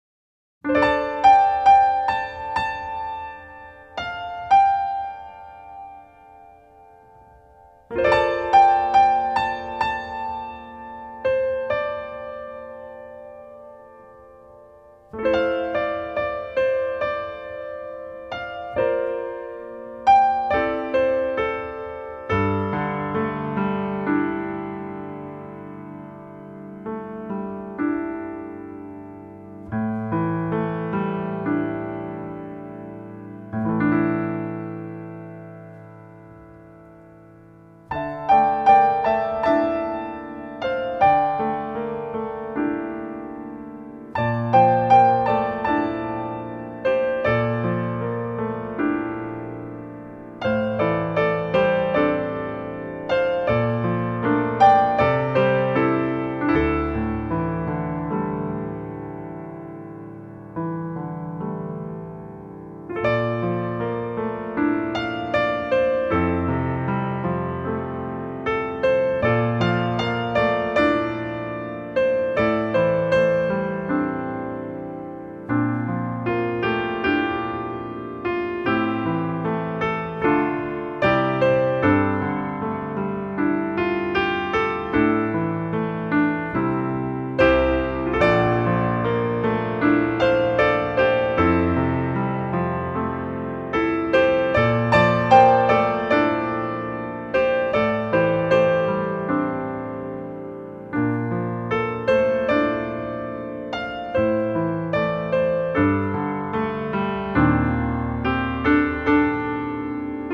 ★ 融會貫通東西方樂器、傳統與現代的跨時代完美樂章！
★ 輕柔紓緩的美麗樂音，兼具令人震撼感動的發燒音效！